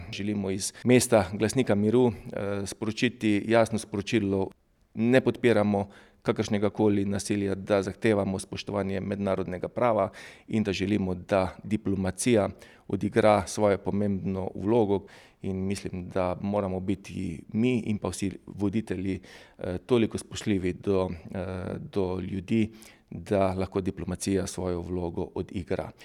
IZJAVA TILEN KLUGLER 2.mp3